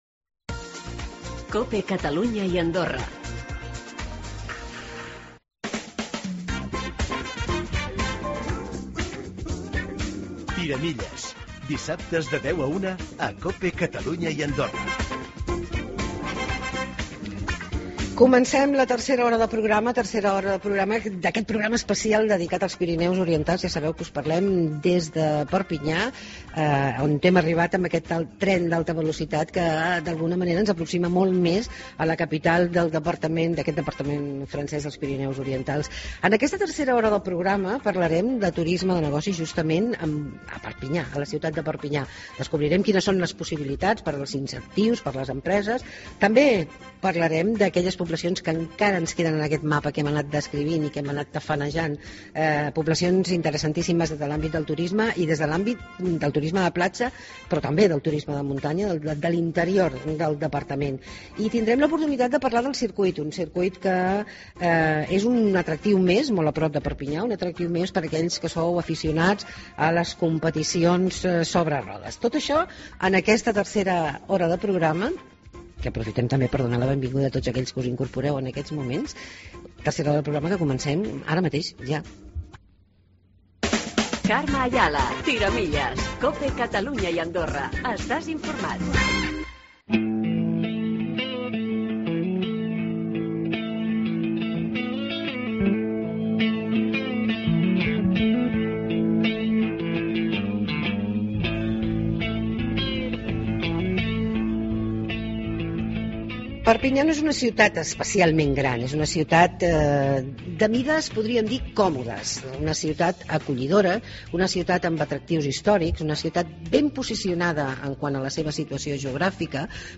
Programa especial desde Perpiñan (Tercera hora, primera parte)